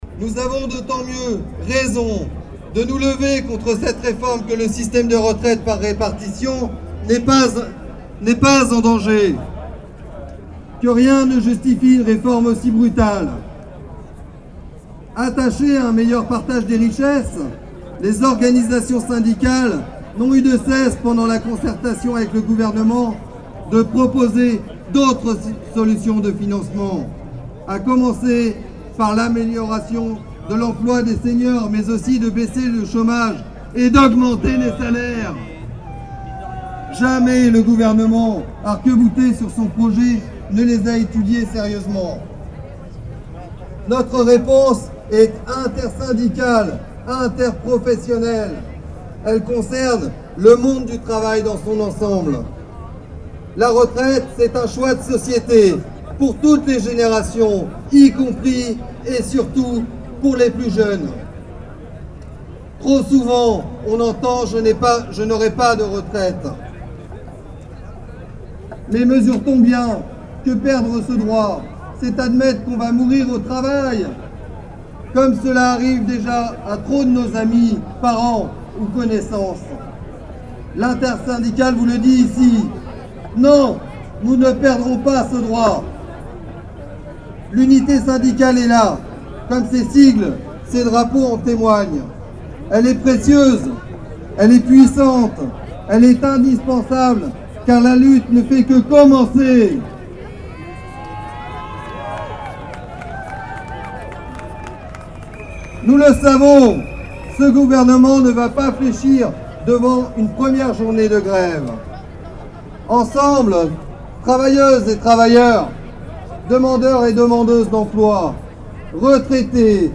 Manifestation contre la réforme des retraites au Mans